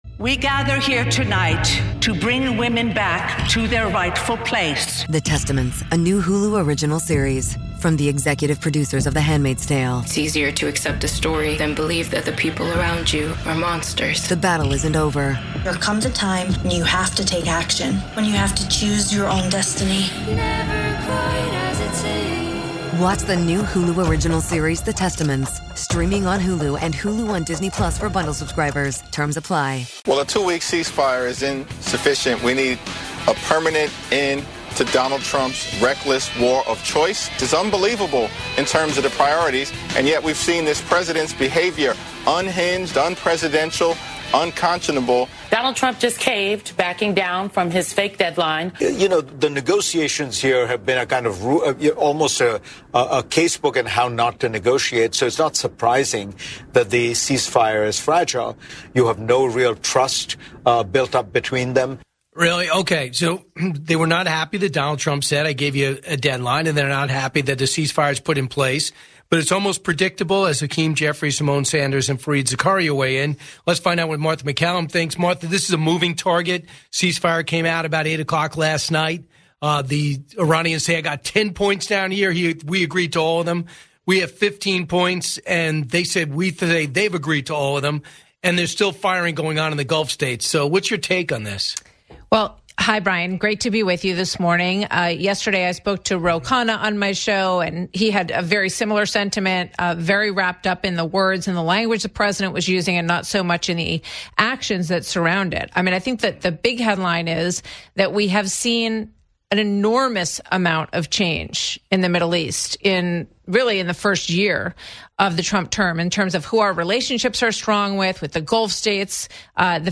Martha MacCallum joins Brian Kilmeade to break down the fragile "ceasefire" in the Middle East as Iran continues drone attacks on U.S. allies. They discuss the shocking refusal of European nations to allow U.S. forces to use our own bases and why the media is obsessed with "war crimes" while ignoring the reality on the ground.